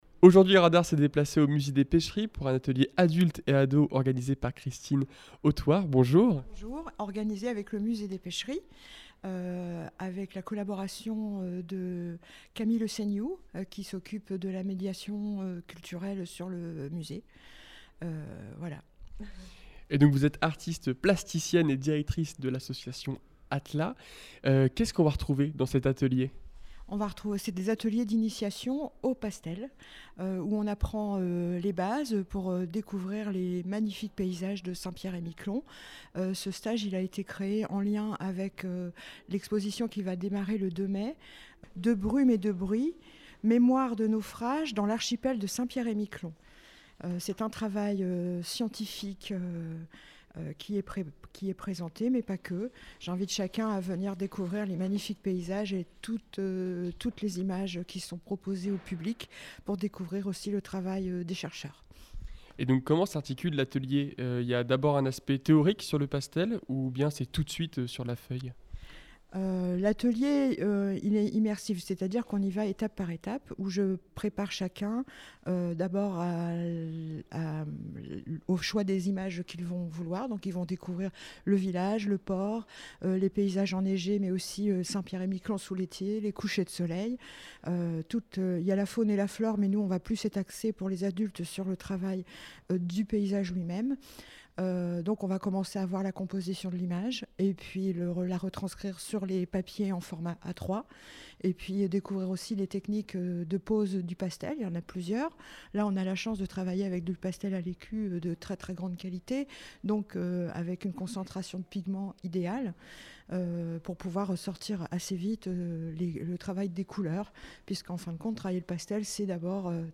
Les interviews Radar Actu Interview fécamp podcast